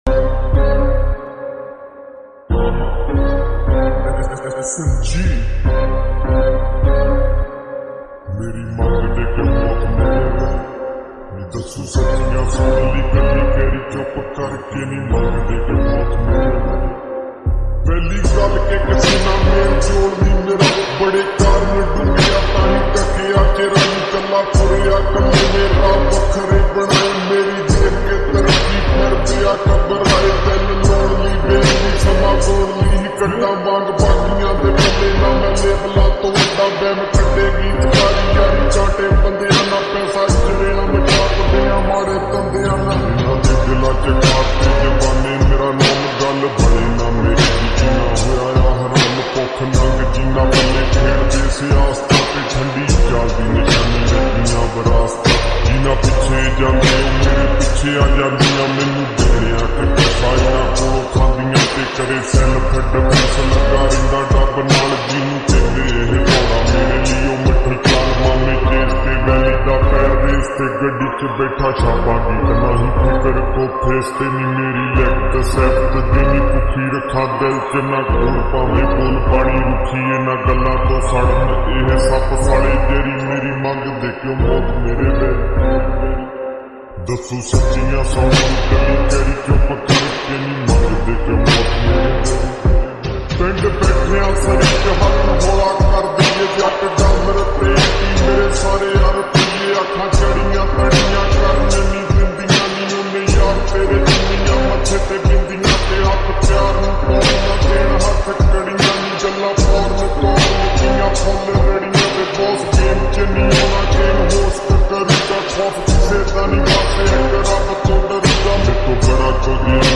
SLOWED AND REVERB
PUNJABI ULTRA HD SLOWED SONG